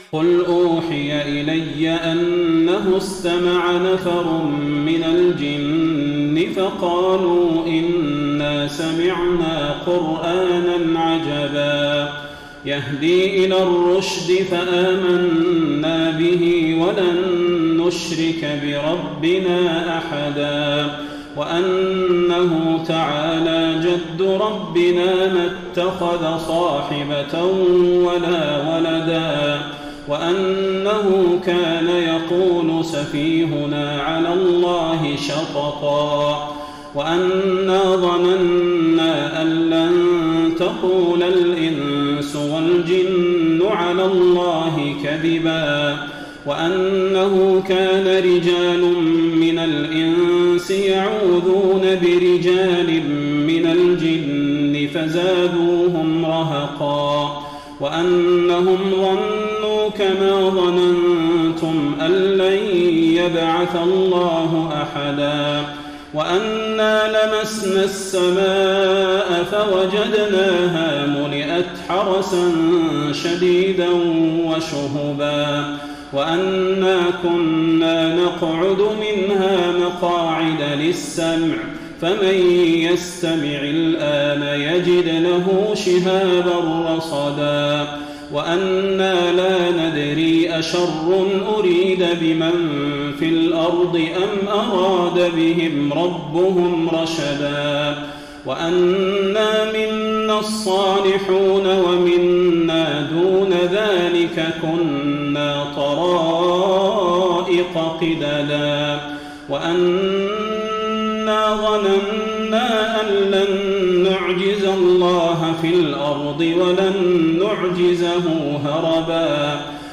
سورة الجن | رمضان 1433هـ > السور المكتملة للشيخ صلاح البدير من الحرم النبوي 🕌 > السور المكتملة 🕌 > المزيد - تلاوات الحرمين